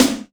STGSNARE.wav